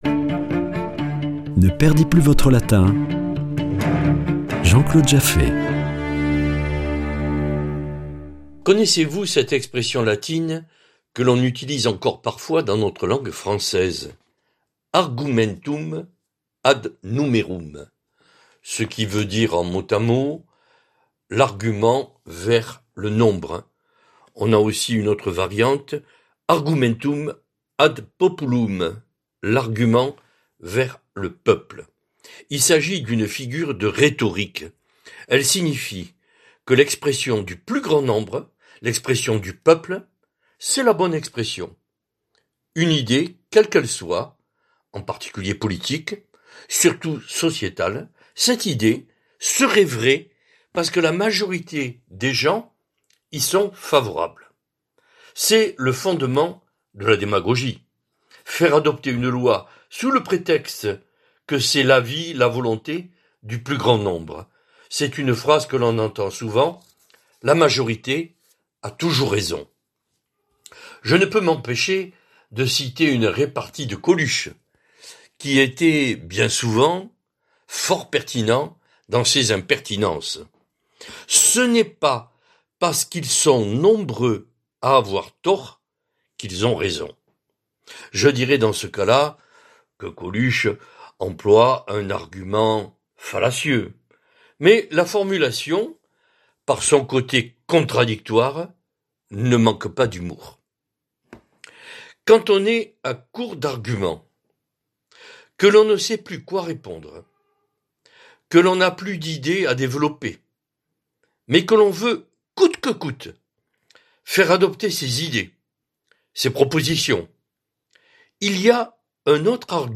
Chronique Latin
Chroniqueur